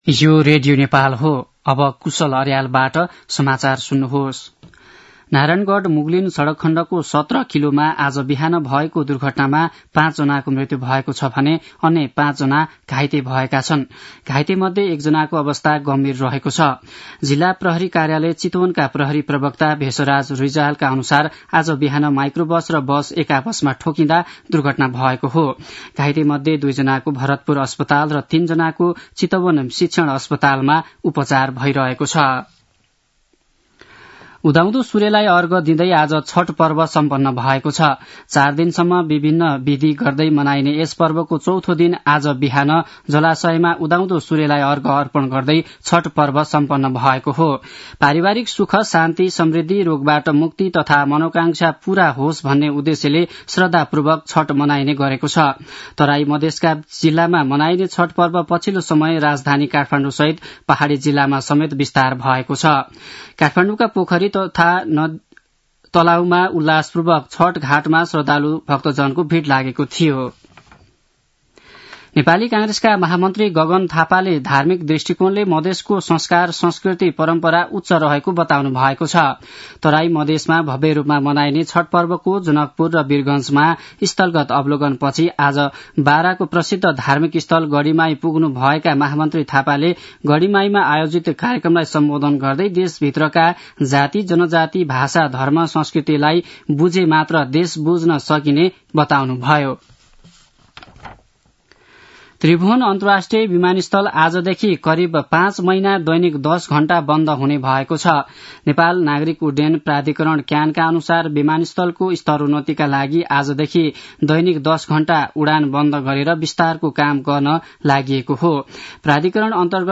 दिउँसो १ बजेको नेपाली समाचार : २४ कार्तिक , २०८१